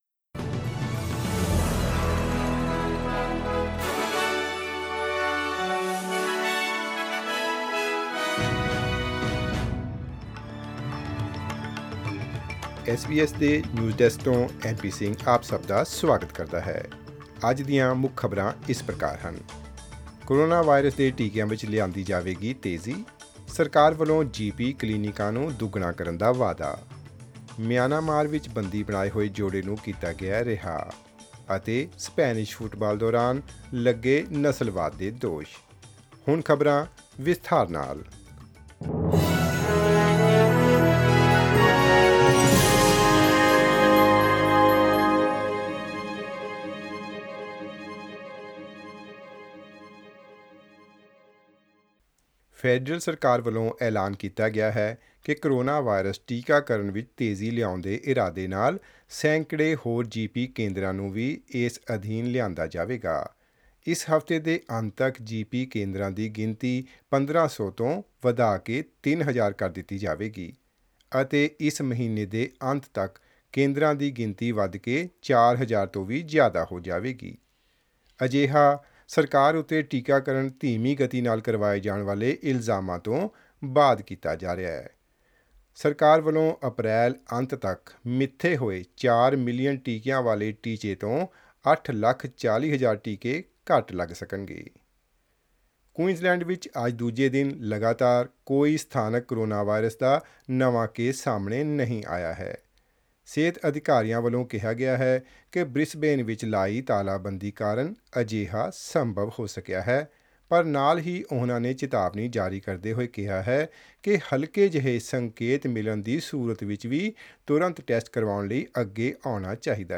Australian News in Punjabi : GP clinics supplying the jab to be doubled in order to accelerate the vaccine rollout